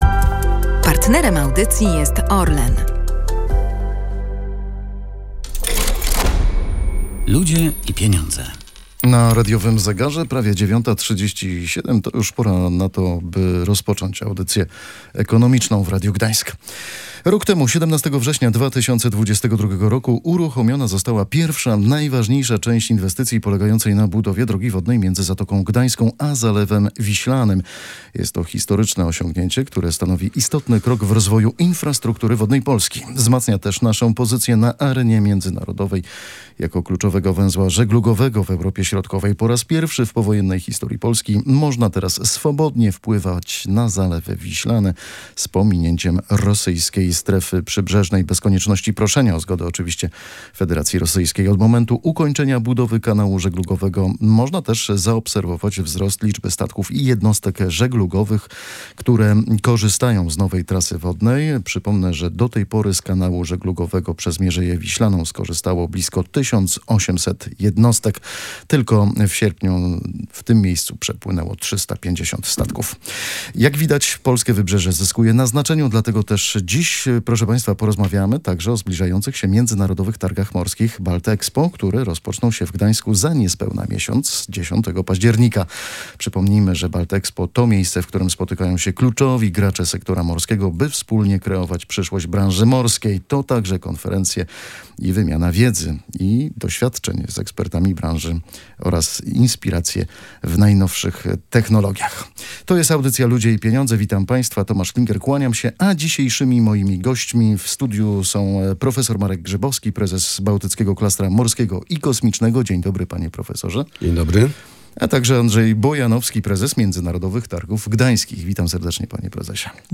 Przełomowy kanał żeglugowy i Targi Baltexpo. Eksperci rozmawiają o rozwoju branży morskiej